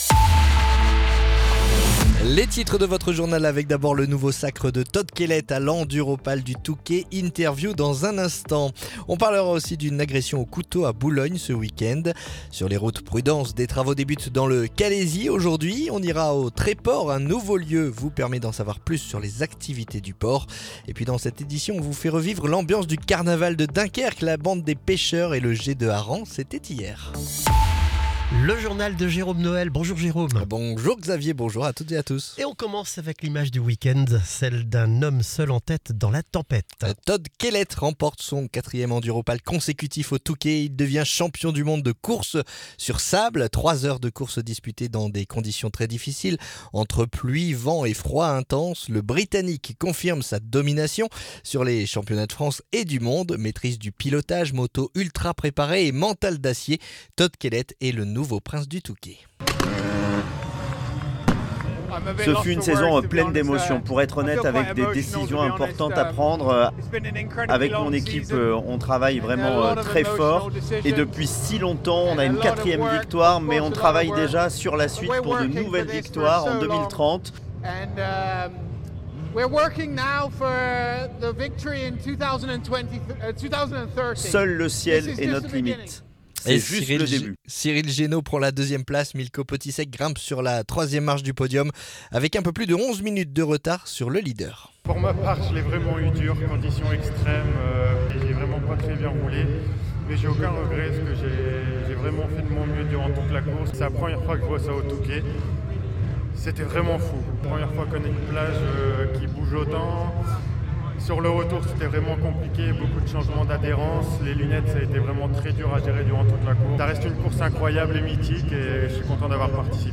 Le journal du lundi 16 février